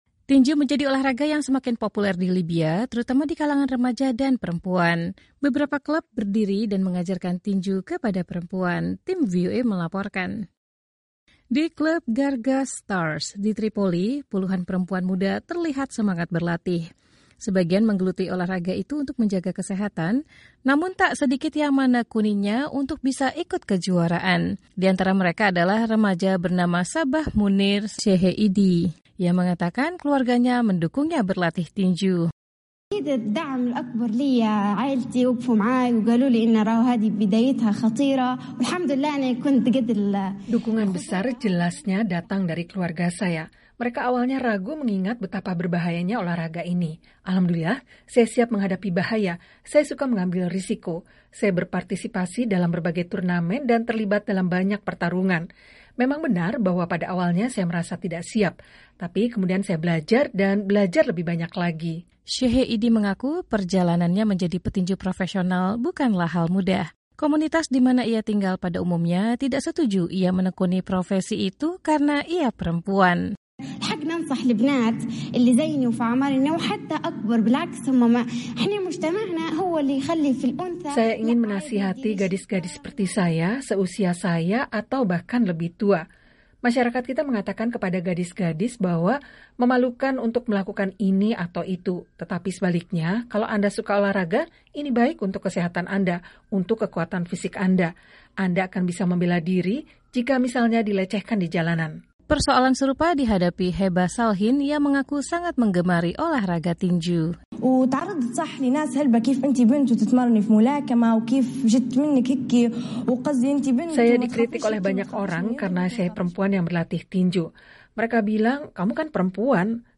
Tinju menjadi olahraga yang semakin populer di Libya, terutama di kalangan remaja dan perempuan Beberapa klub berdiri dan mengajarkan tinju kepada perempuan. Tim VOA melaporkan.